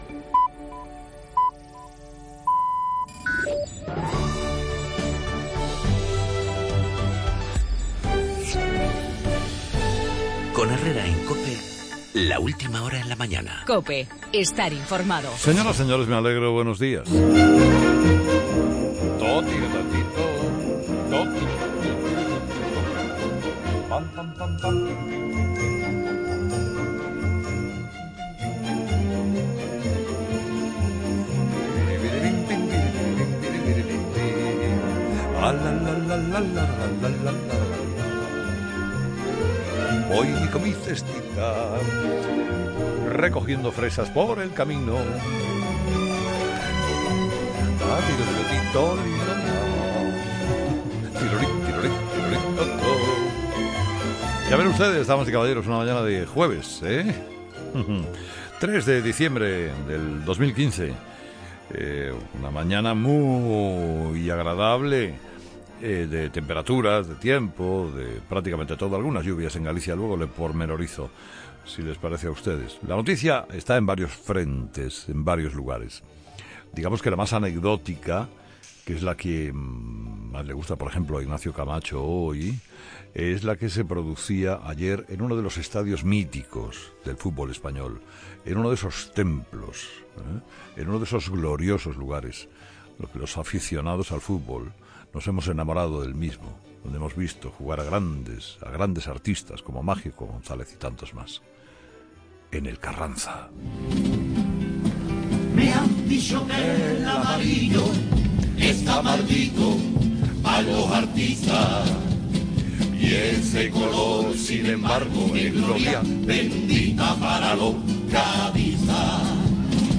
La alineación del Madrid en la Copa del Rey, con el himno oficioso del Cádiz y la decisión del TC de anular la resolución independentista de Cataluña, en el editorial de Carlos Herrera.